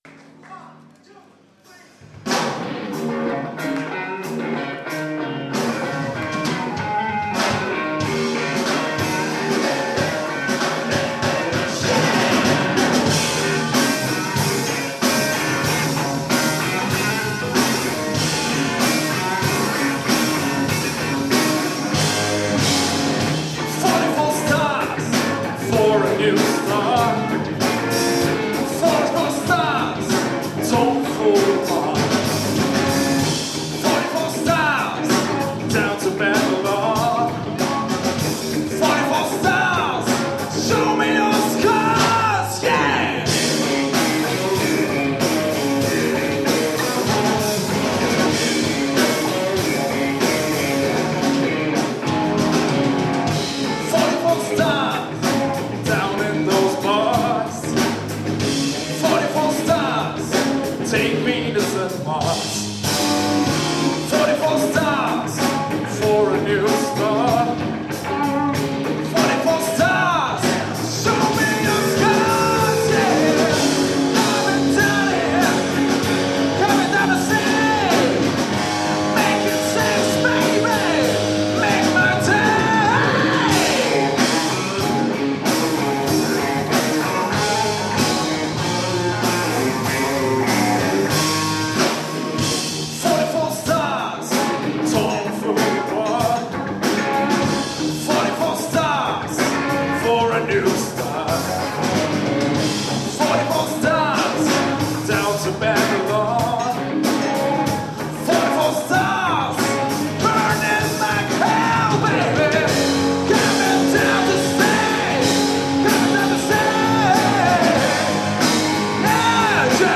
"SCHOOL OF ROCK" - Rockkonzert an unserer Schule
Im Rahmen eines Maturaprojektes fand am 14.2.2013 im Theatersaal des Österreichischen St. Georgs-Kolleg ein gut besuchtes Konzert unter dem Titel "SCHOOL of ROCK" statt.